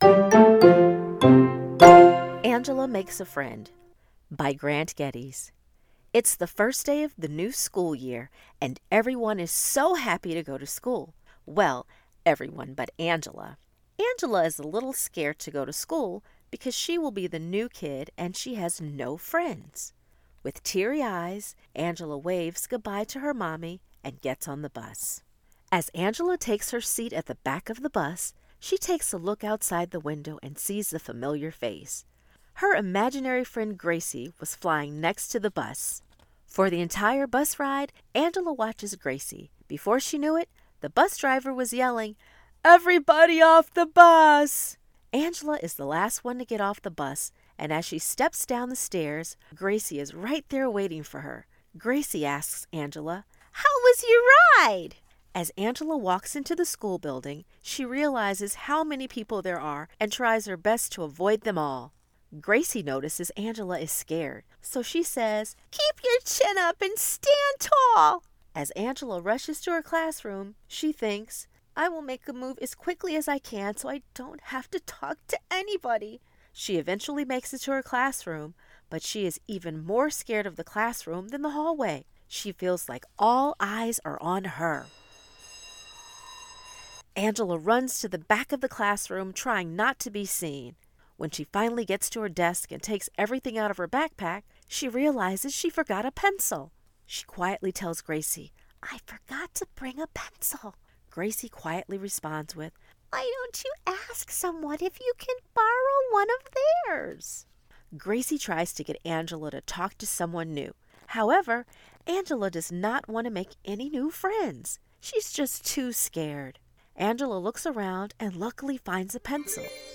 Audiobooks
Angela-Makes-A-Friend-Audiobook.mp3